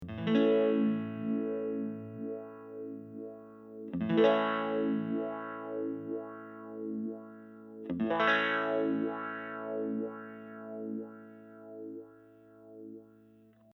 The sweep modes are ÒchoppyÓ and Òsmooth,Ó which refer to how quickly the sweep travels along the frequency range.
Sweeping the HF Setpoint control:  Clock Trigger, Smooth Mode